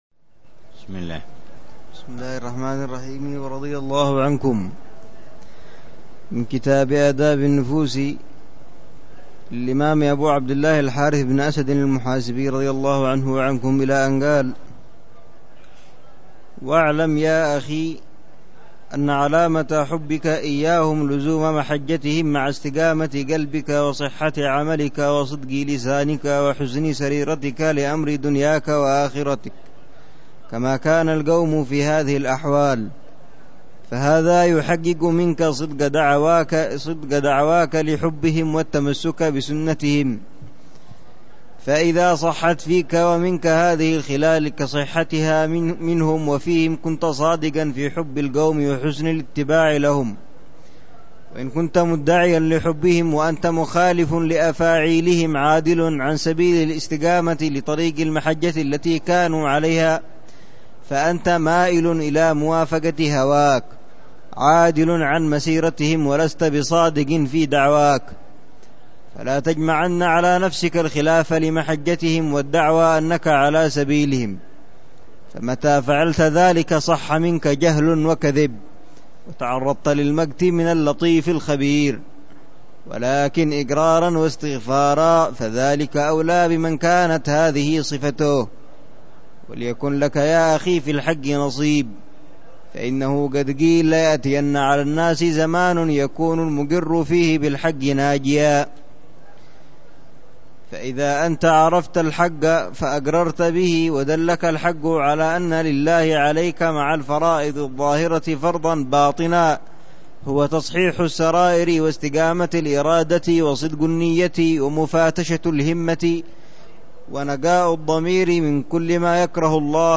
شرح كتاب آداب النفوس - الدرس الثالث - علامة حب الصالحين